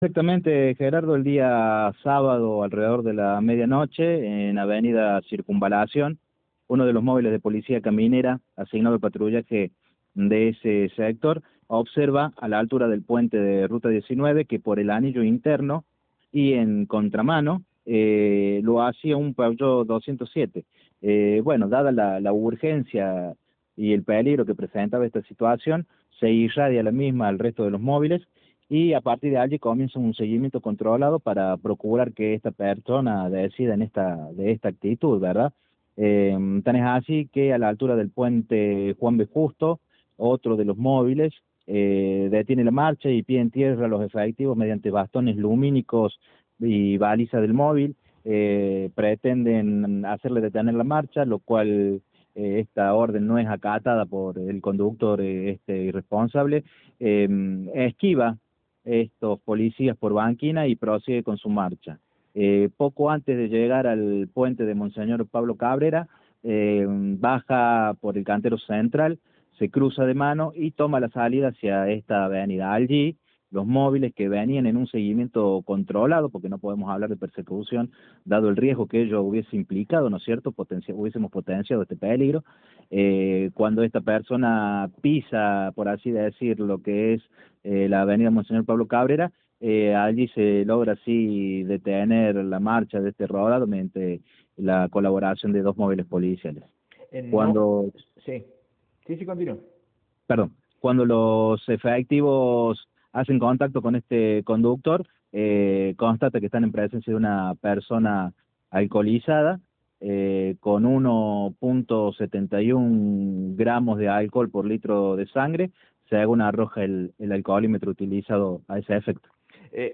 Audio: Comisario Carlos Barrionuevo (Jefe de la Policía Caminera).